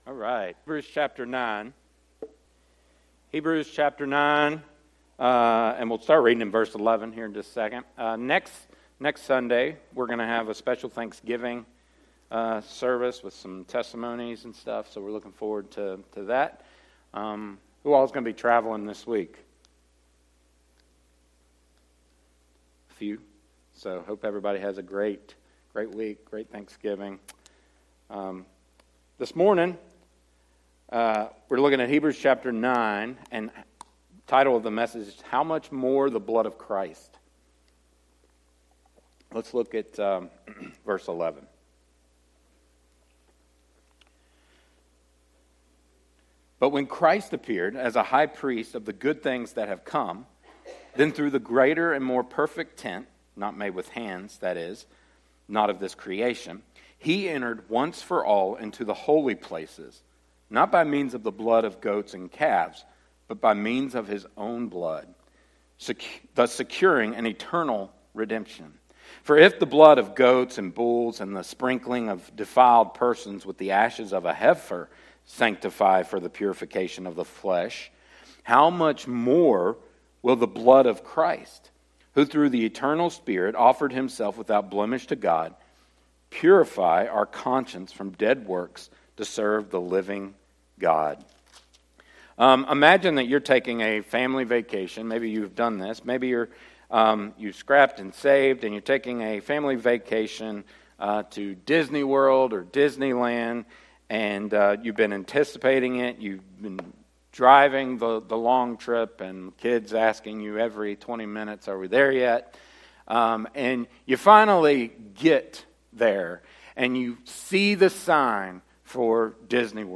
sermon-audio-trimmed-3.mp3